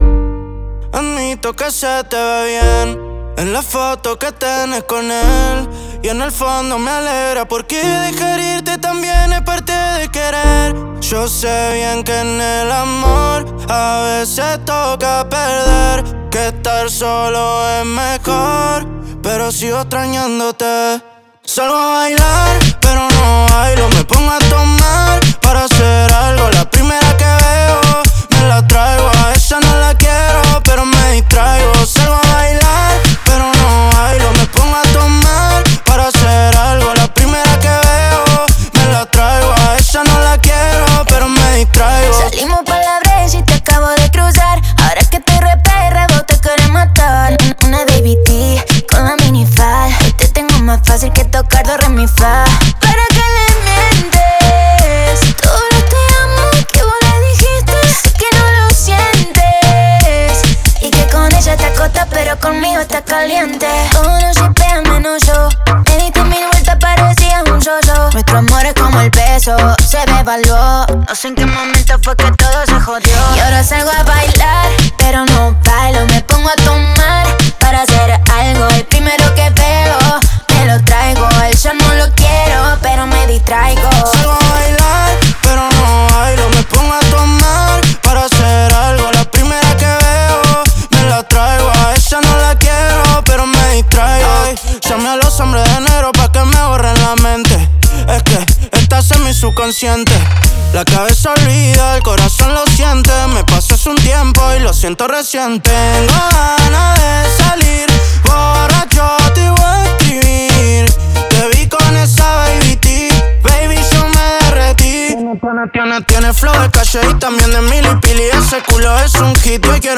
reggaetón.